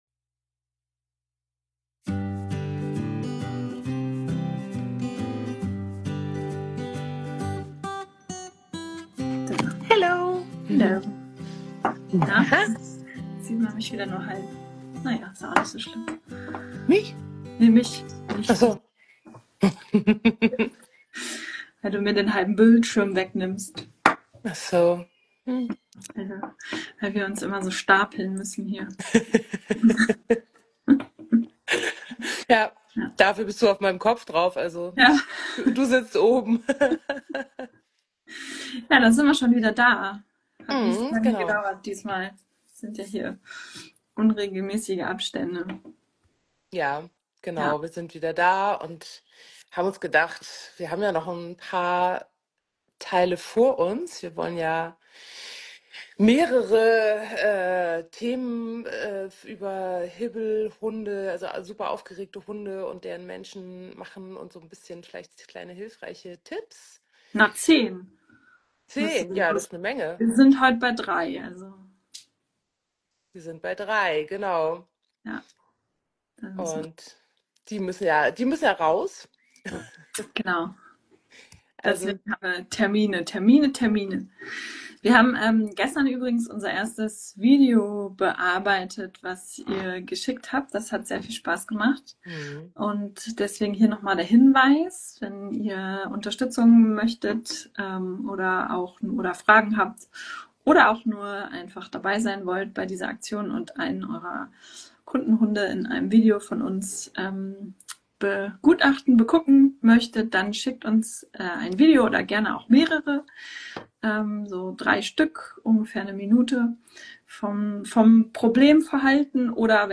In unserer Instagram Live Reihe beschäftigen wir uns im Moment intensiv mit den Besonderheiten von Hibbelhunden in Deinem Training. In der dritten Folge geht es die dazugehörigen Menschen und wie es denen so geht mit einem besonders aufgeregten Hund.